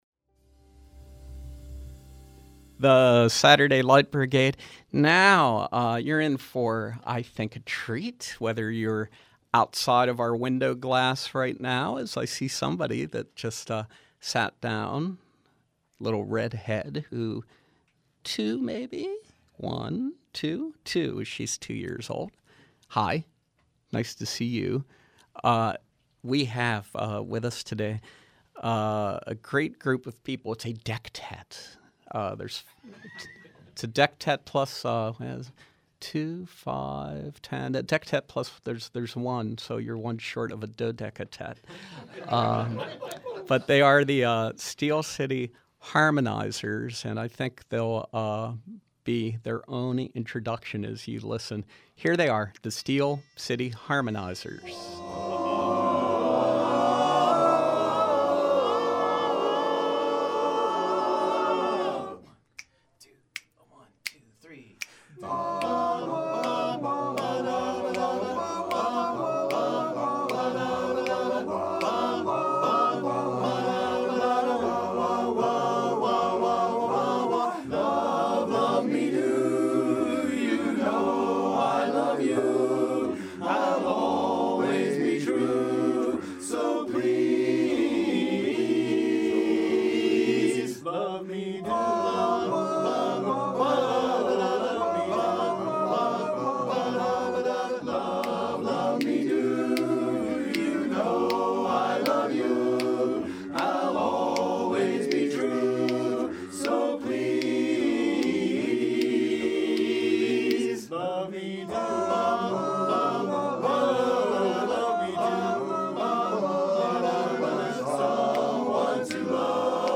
Live Music: Steel City Harmonizers
From 3/9/13: Barbershop harmony with members of the Steel City Harmonizers